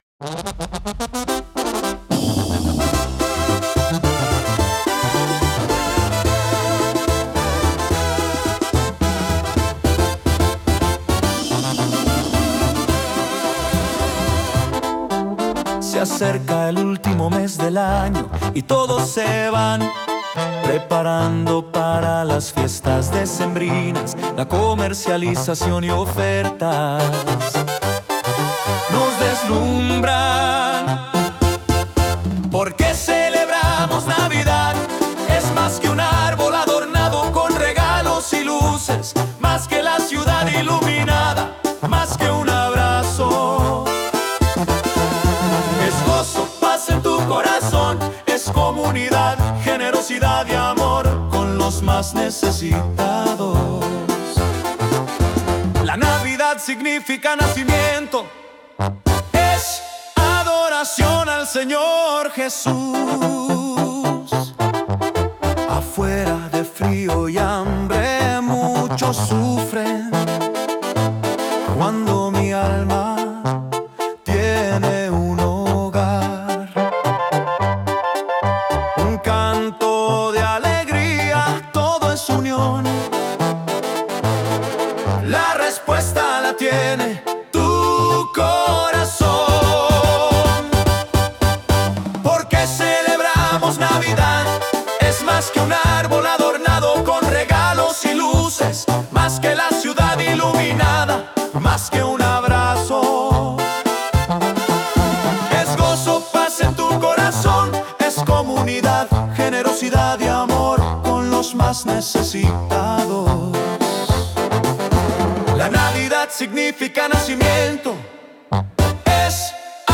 Banda